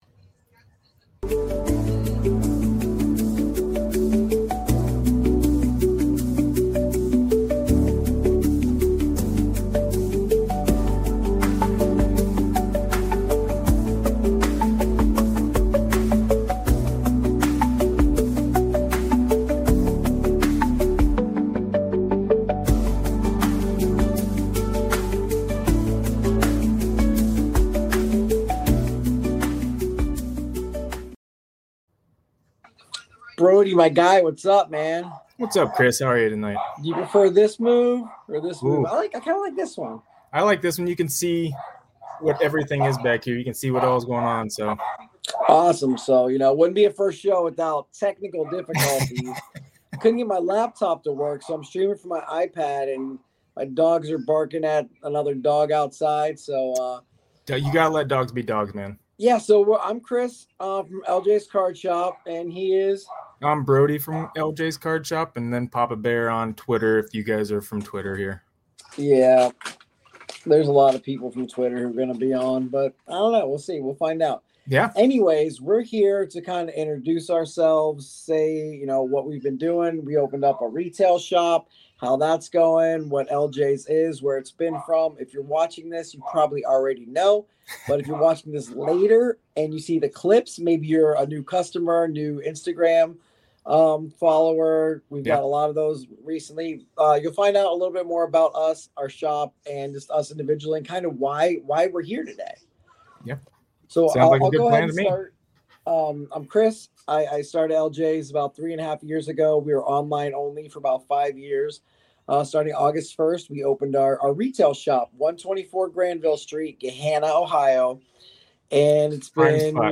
In this special episode, we're sitting down with the team behind LJ's Card Shop as they prepare to open their new retail location in Gahanna, Ohio.